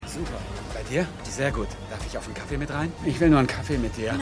Pssica_1x03_MannStreifenhemd.mp3